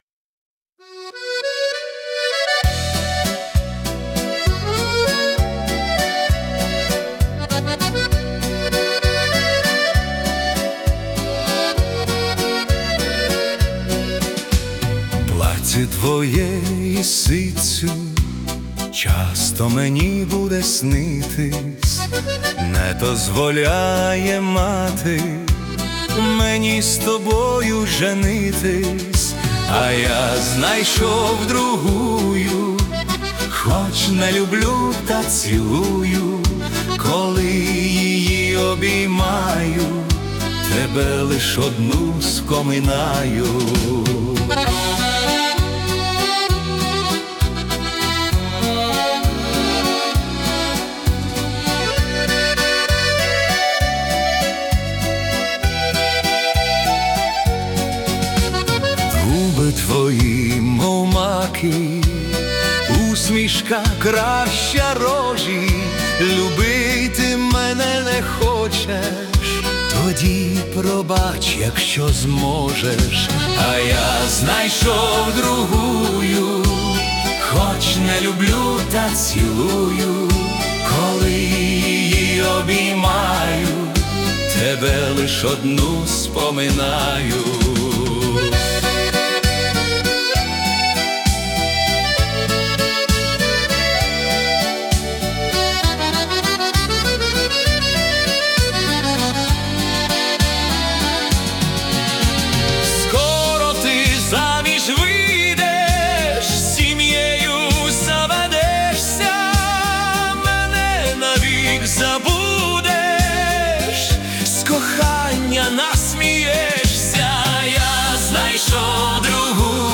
Народний хіт у ритмі 70-х